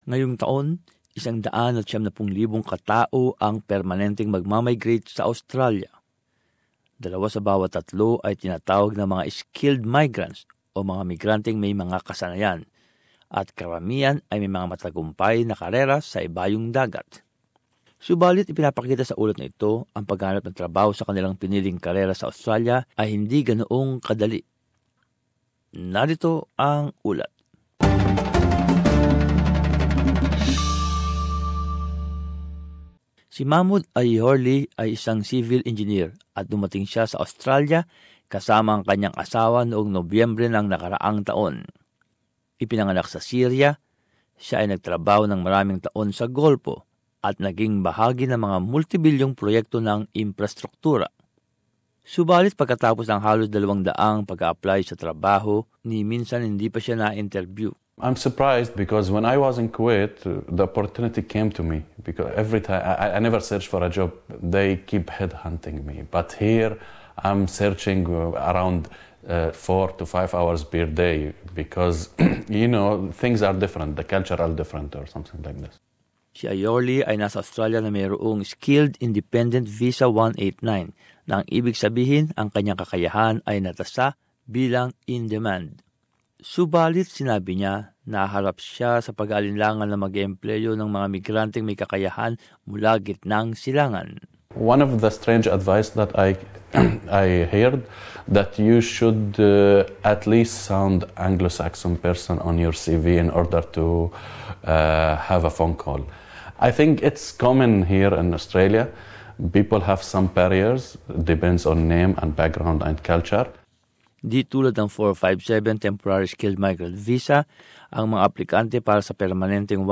But as this report shows, finding work in their chosen field in Australia isn't always easy.